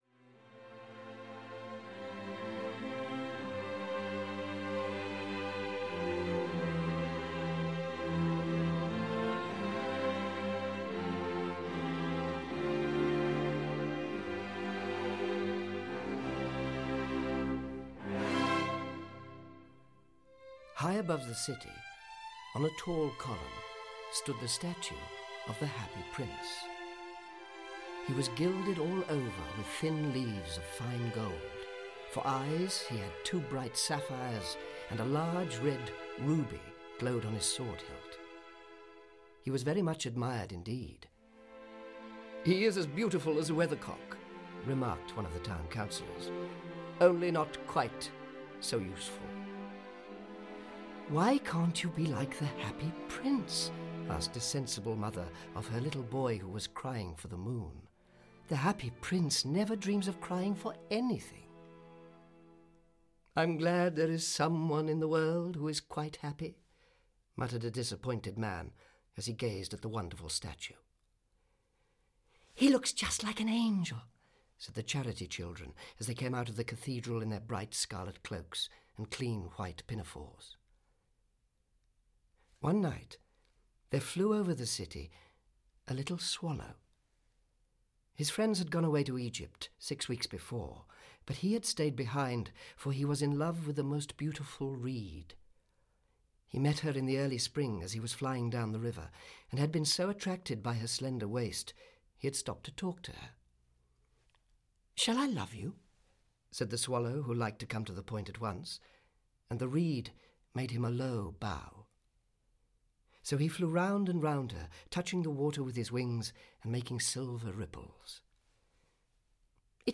Audio kniha
• InterpretAnton Lesser